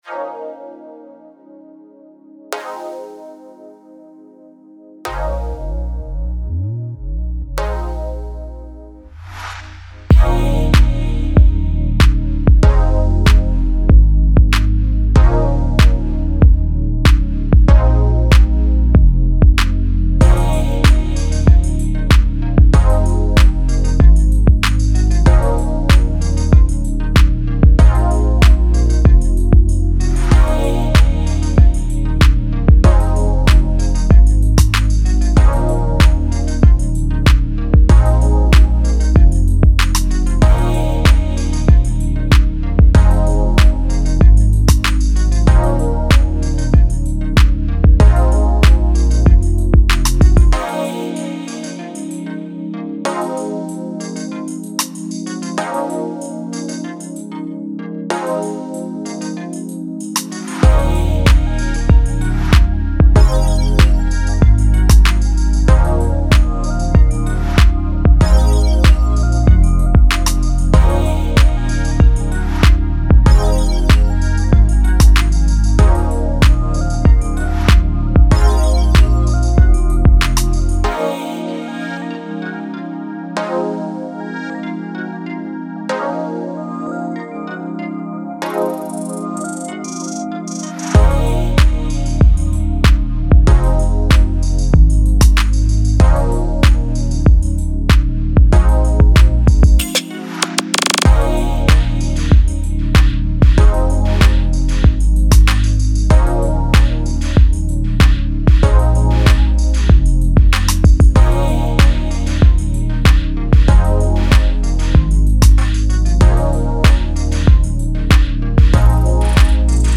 Description : Fits into Hip Hop/R&B/Pop sound